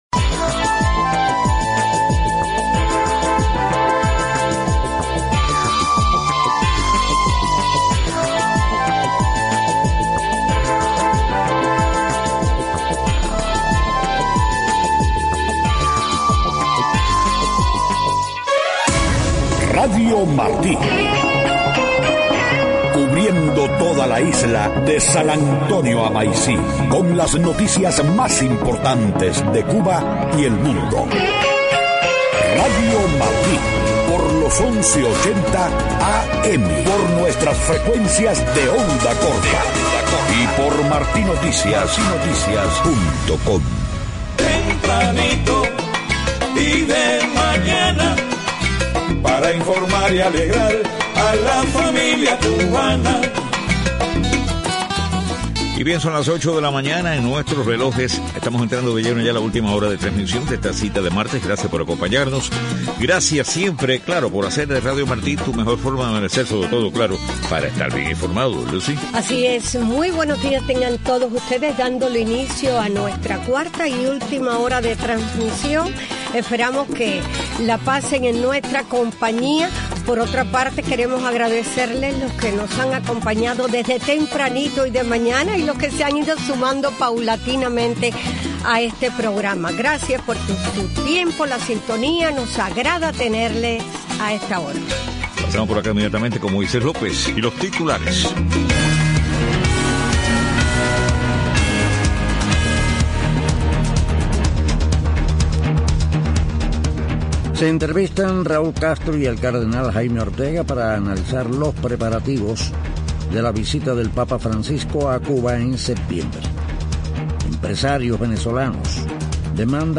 8:00 a.m. Noticias: Se entrevista Raúl Castro y el cardenal Jaime Ortega para analizar preparativos de la visita del papa Francisco a Cuba en septiembre. Empresarios venezolanos demandan al banco Central su obligación de informar al país sobre indicadores económicos.